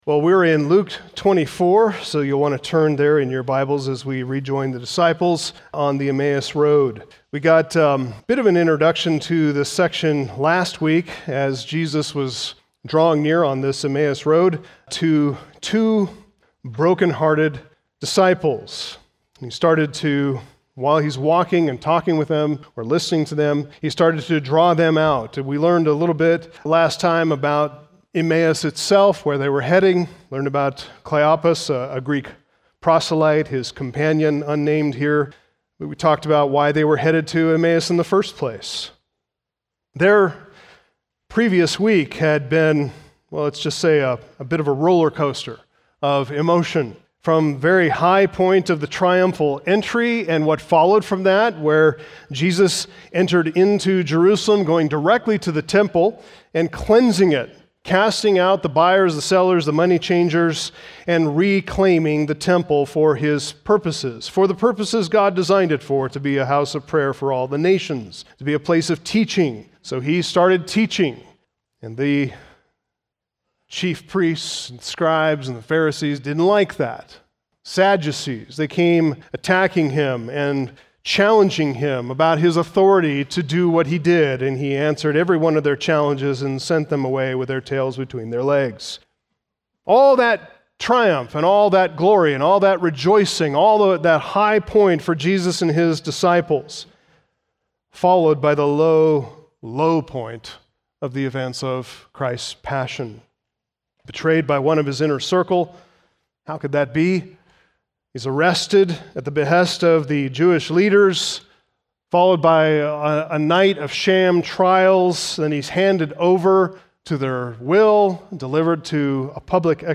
Sermons The Gospel of Luke